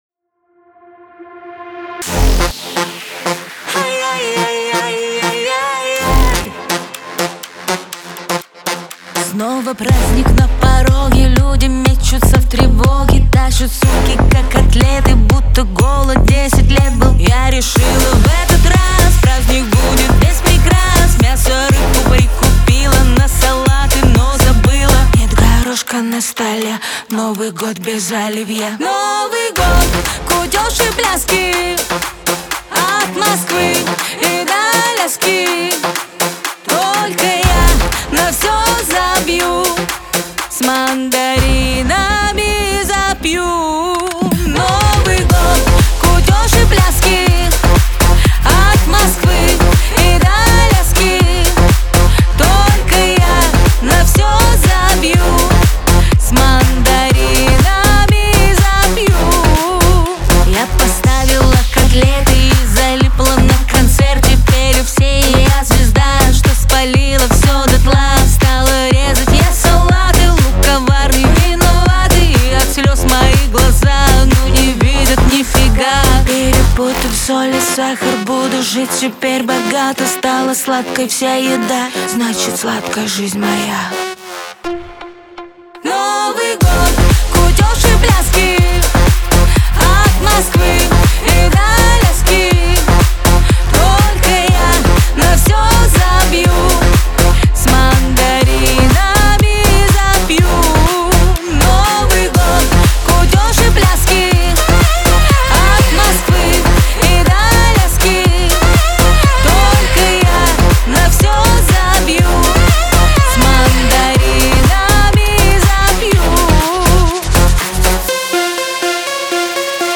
Главная » Файлы » Аранжировки